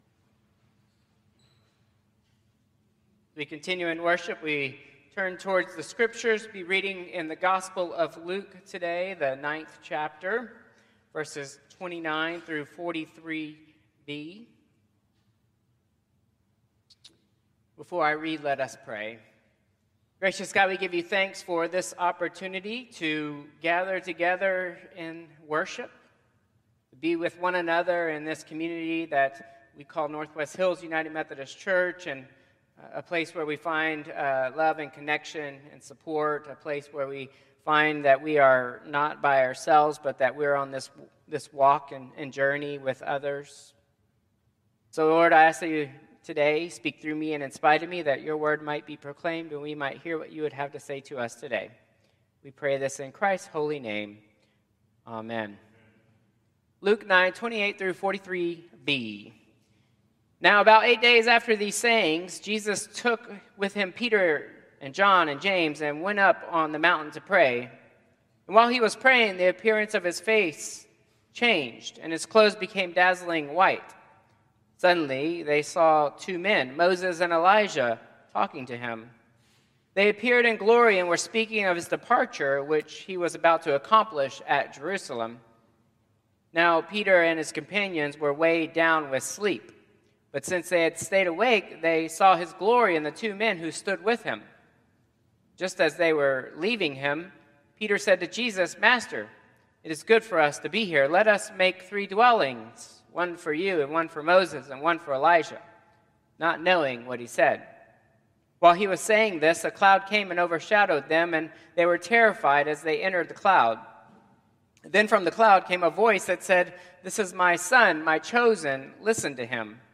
Traditional Service 3/2/2025